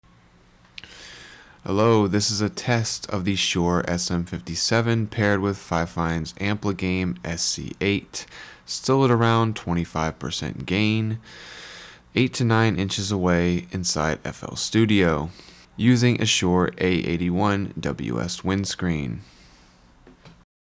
The SC8 has this subtle crackly character and sounds a bit distorted vs. the very clean signal coming out of the Volt 2.
Fifine_Ampligame_SM57_FL_Studio_Trimmed.wav